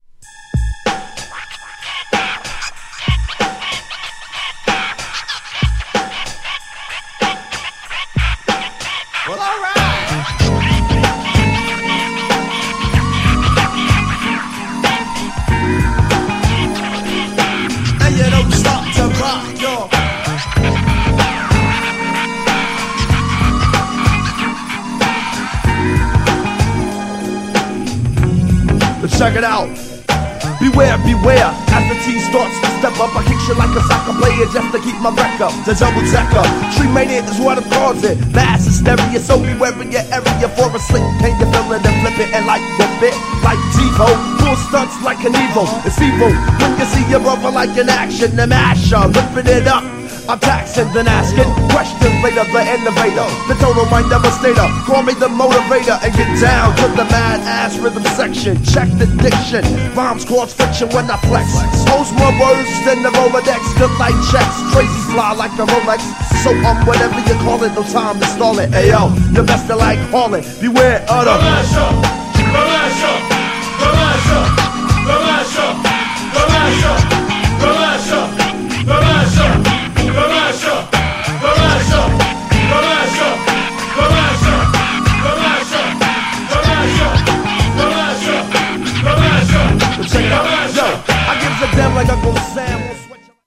よりJAZZYなREMIXもカッコいい!!
GENRE Hip Hop
BPM 91〜95BPM